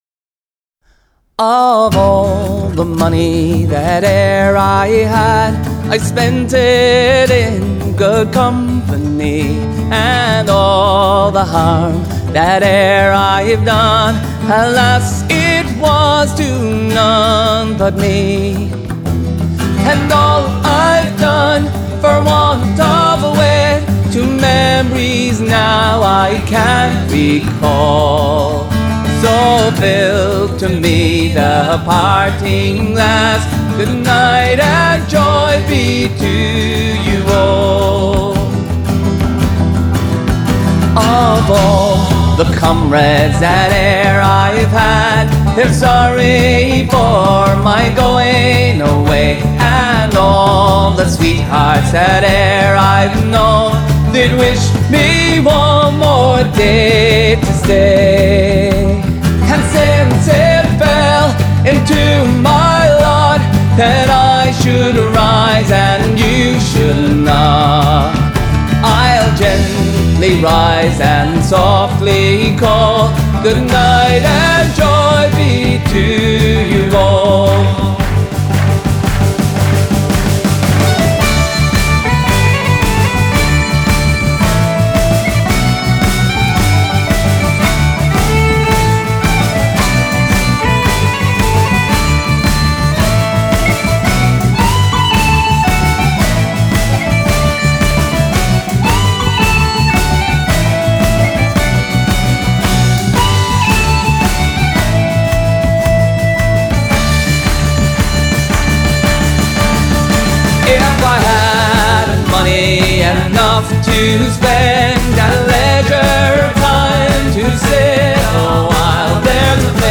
Genre: Folk/Country/Pop